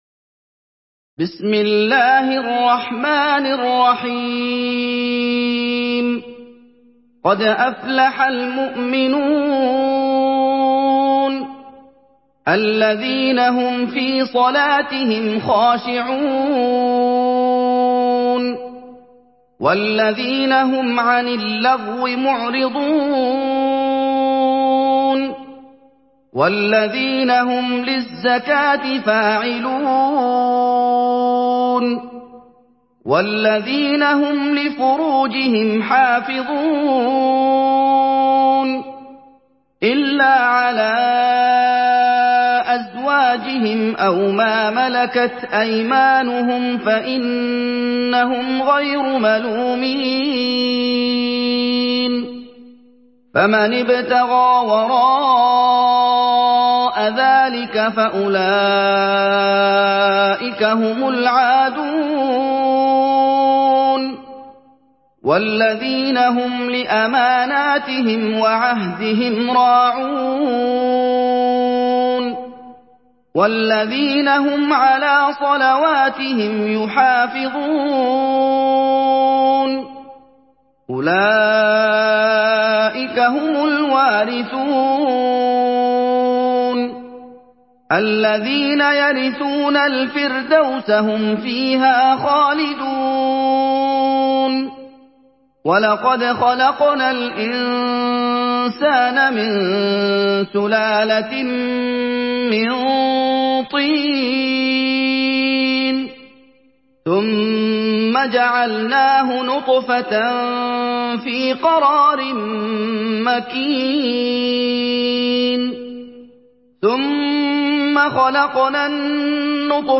Surah Al-Muminun MP3 by Muhammad Ayoub in Hafs An Asim narration.
Murattal